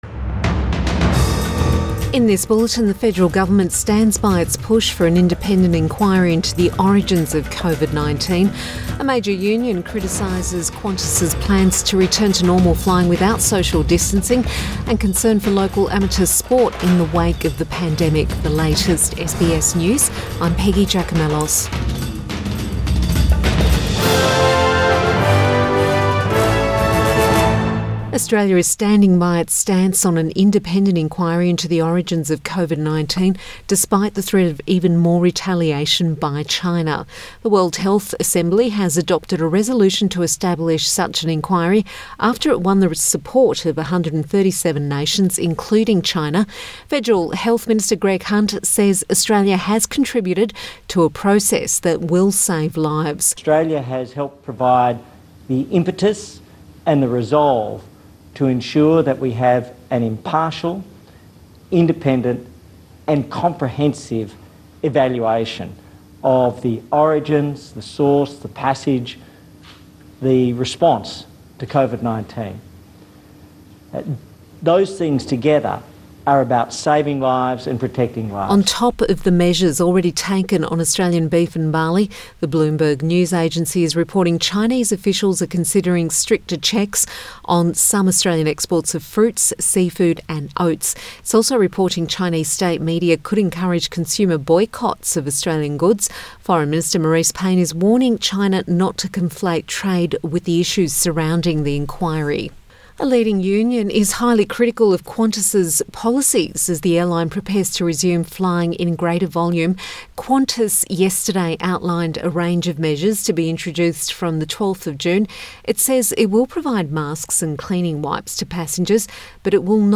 PM bulletin May 20 2020